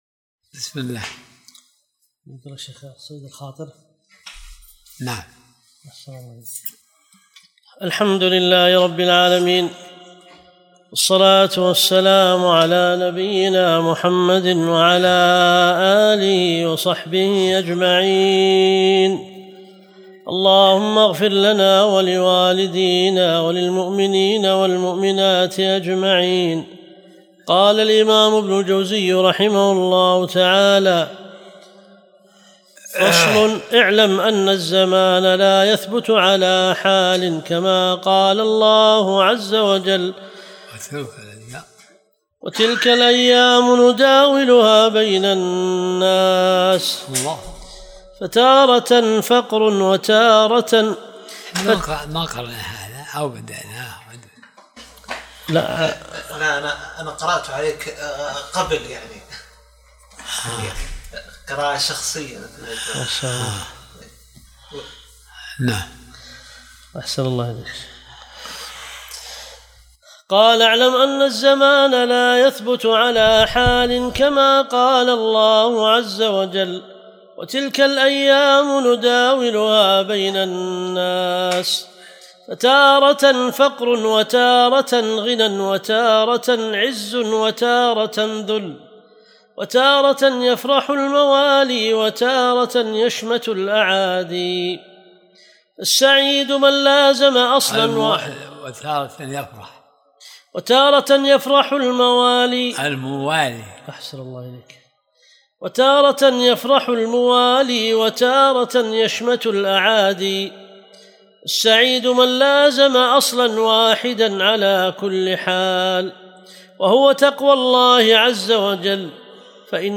درس الأربعاء 74